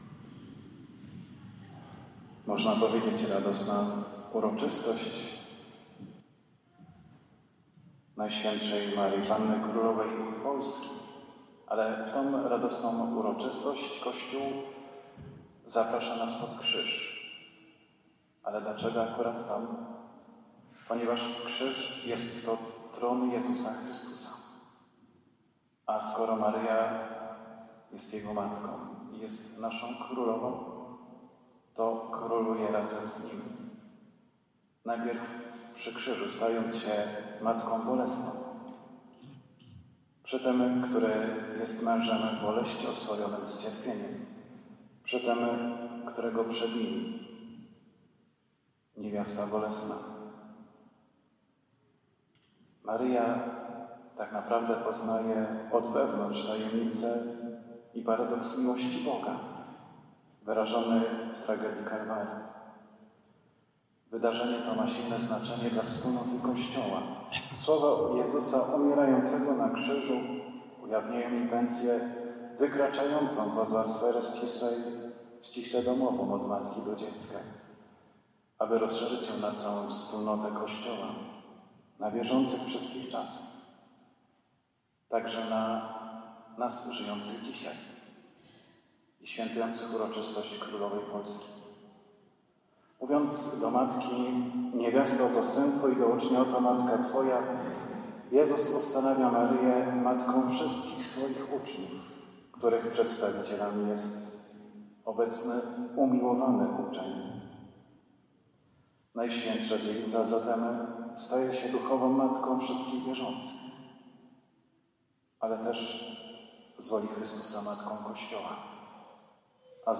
MSZA ŚWIĘTA W INTENCJI OJCZYZNY – Parafia Szprotawa
homilia-3-maja-2025.mp3